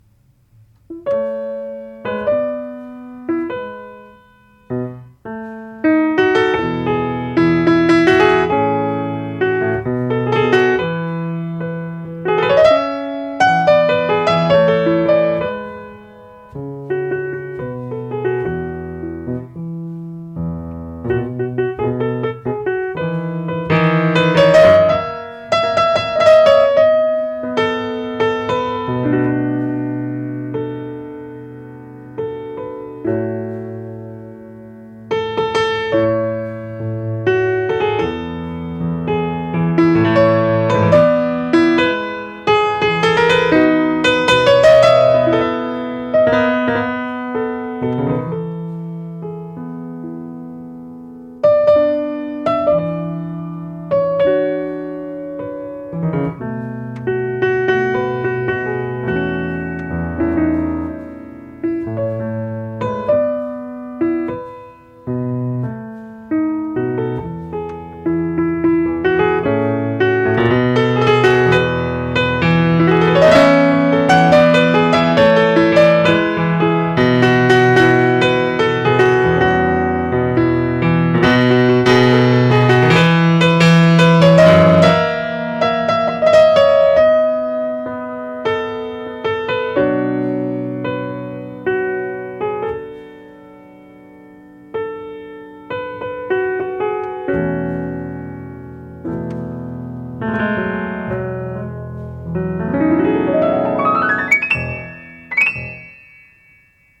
para piano